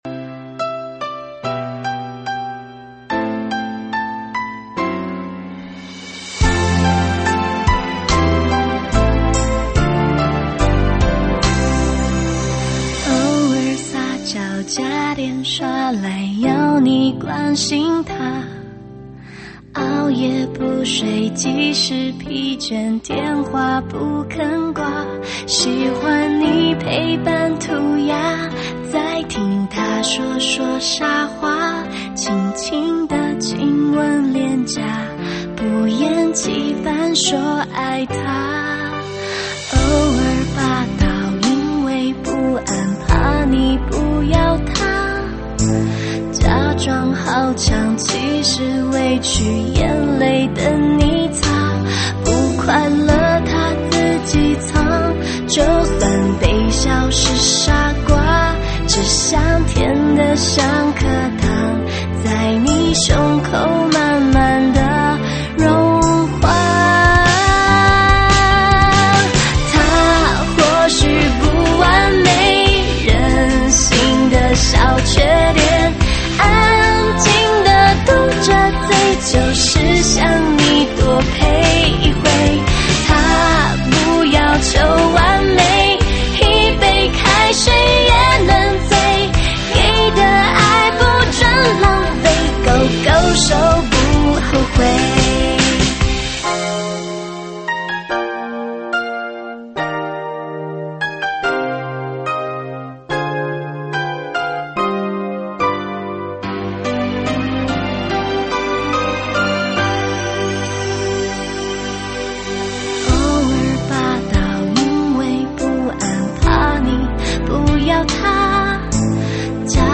经典 抖音 伤感情歌 暖场